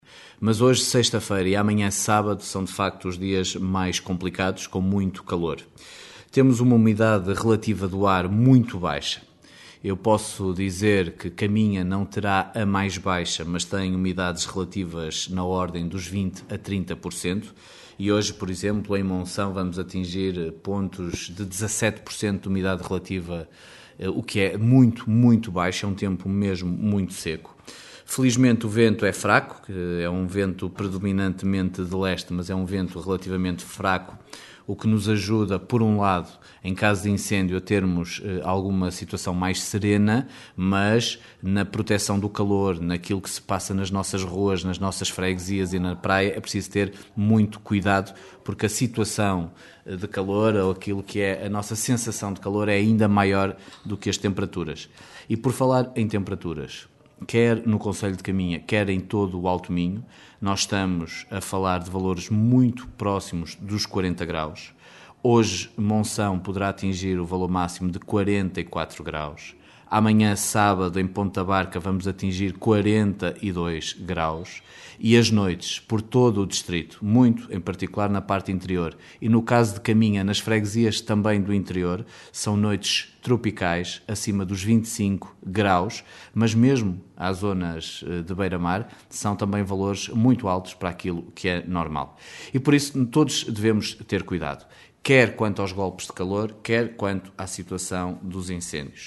Em declarações ao Jornal C o presidente da Comissão Distrital de Proteção Civil, diz que a situação de calor se vai manter durante o fim de semana.
Miguel Alves faz o ponto da situação.